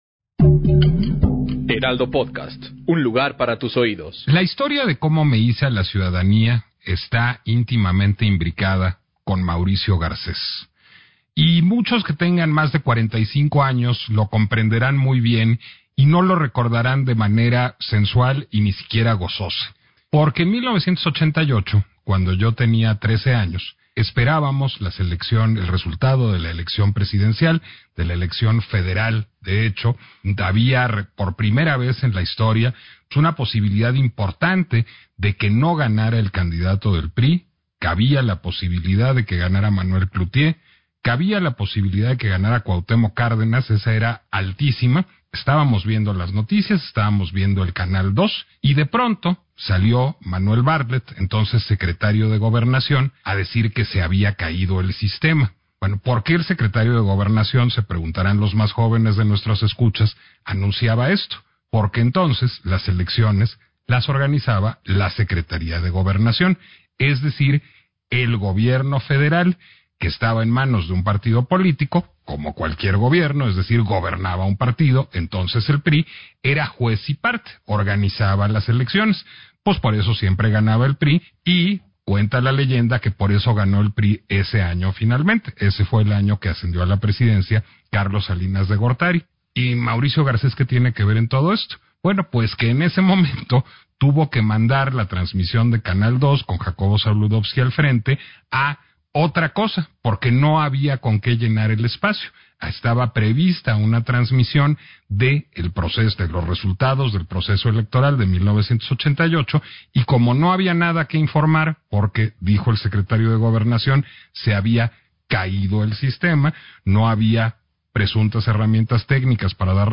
La autonomía del INE es la defensa de una conquista democrática: Lorenzo Córdova en entrevista con Nicolás Alvarado
Entrevista-LCV.mp3